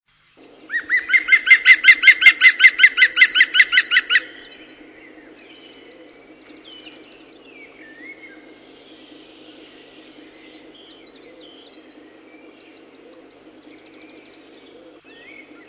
Grünspecht
Grünspechte geben laute, weithin schallende Rufe - das so genannte Wiehern - von sich. Durch diese Balzrufe finden Grünspecht-Pärchen zusammen.